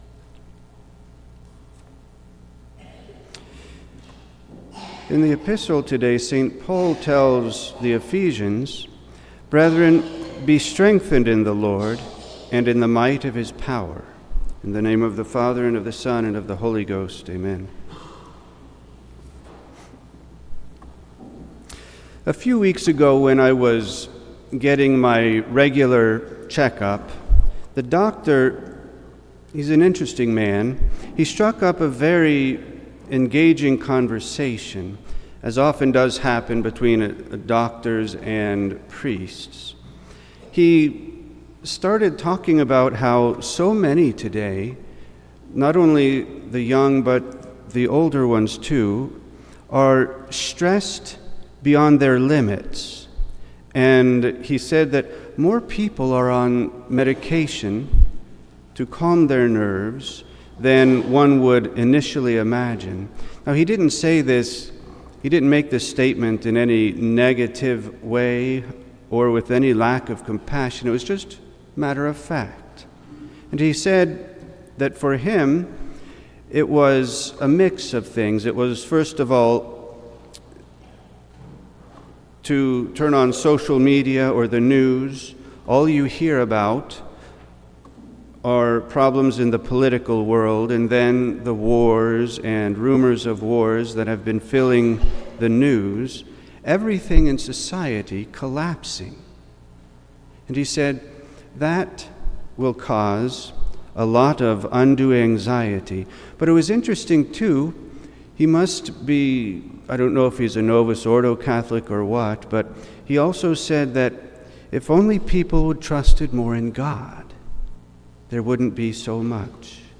Download the Sermon Pentecost XXI Preacher: Bp.